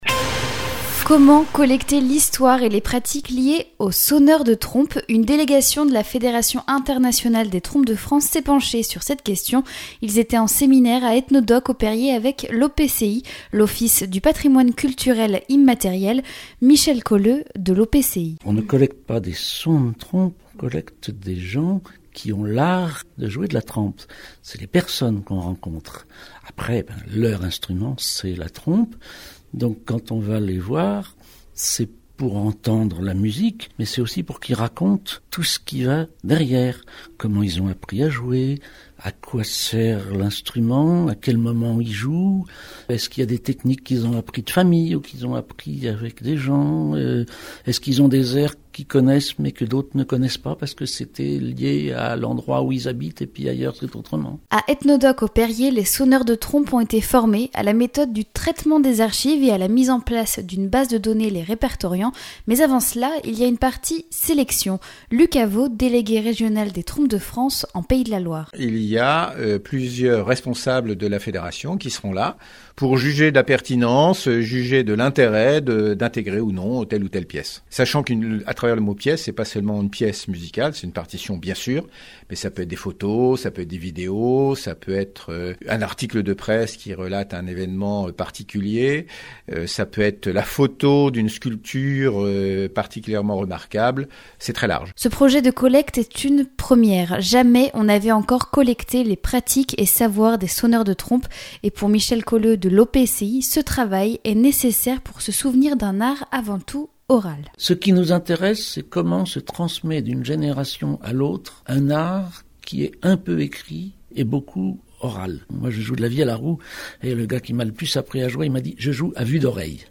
itv_matin_trompes_de_france.mp3